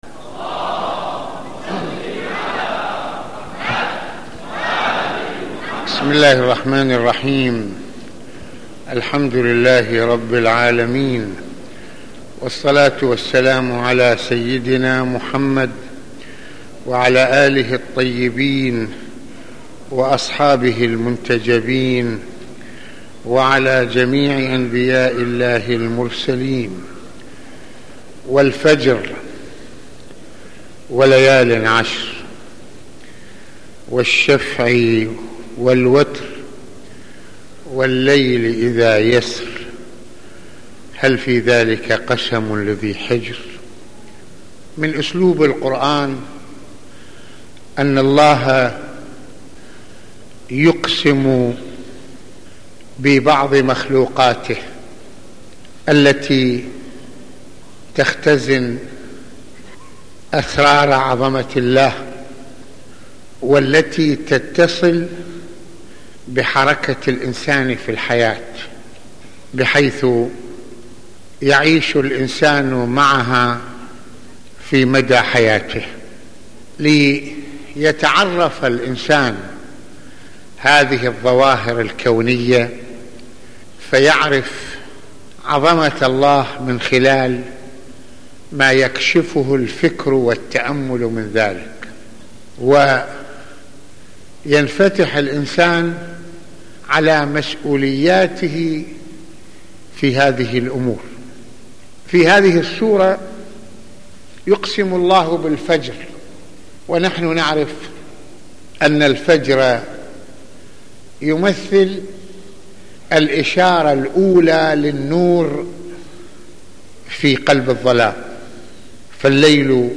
- المناسبة : موعظة ليلة الجمعة المكان : مسجد الإمامين الحسنين (ع) المدة : 33د | 08ث المواضيع : {والفجر وليالٍ عشر} - أسلوب القرآن بالقسم ببعض مخلوقات الله تعالى - كيف نعرف عظمة الله من خلال الظواهر الكونية ؟.